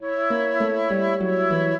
flute-harp
minuet10-11.wav